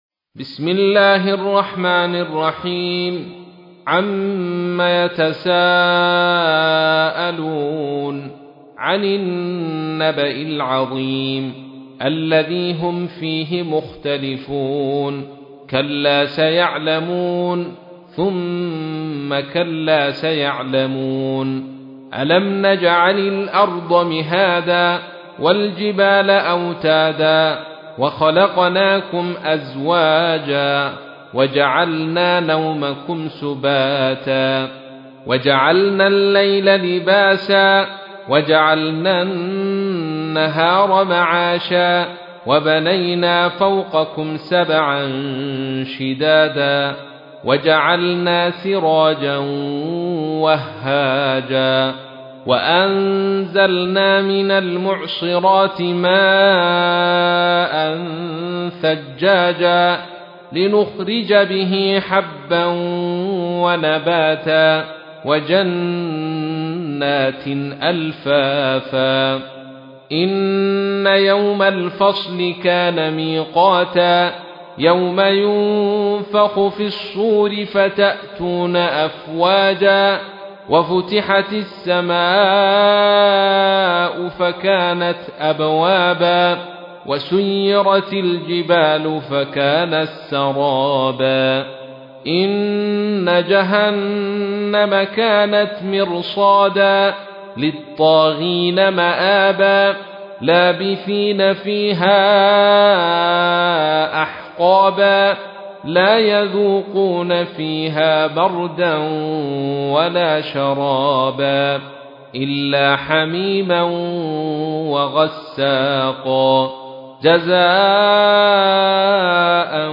تحميل : 78. سورة النبأ / القارئ عبد الرشيد صوفي / القرآن الكريم / موقع يا حسين